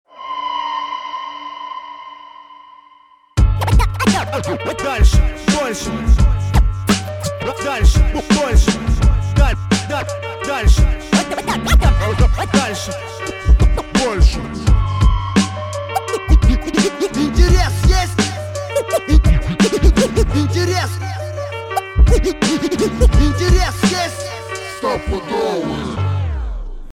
Хип-хоп музыка